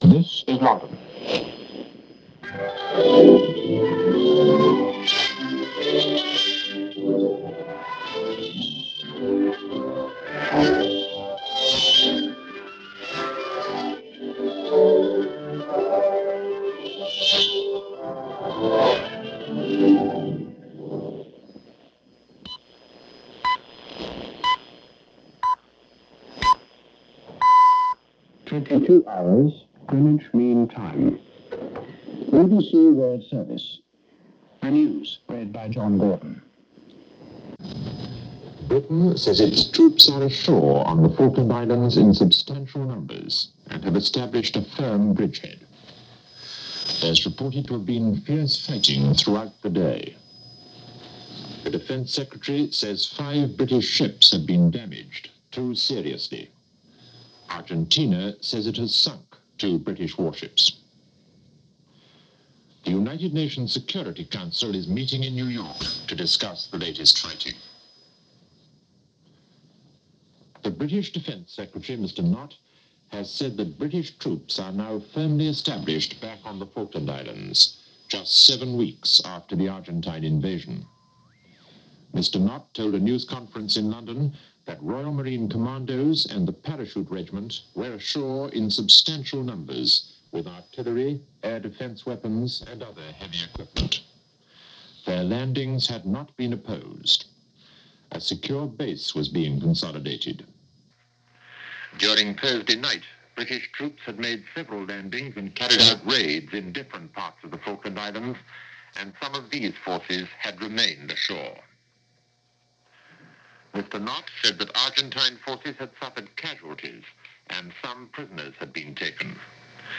For a reminder of what happened on May 21st, here is the initial news via the BBC World Service (Shortwave) describing the landing of troops and the battles that ensued.